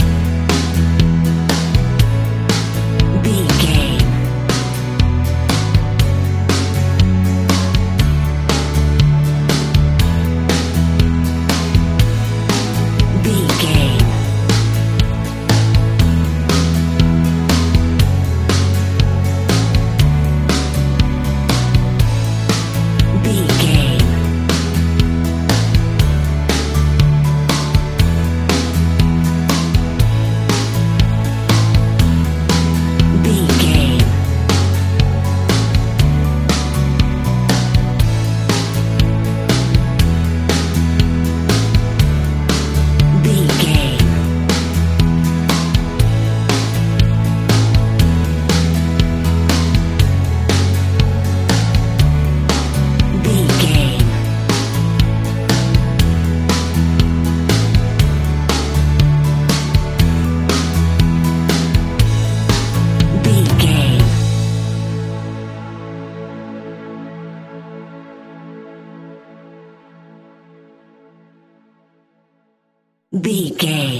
Ionian/Major
energetic
uplifting
cheesy
instrumentals
guitars
bass
drums
organ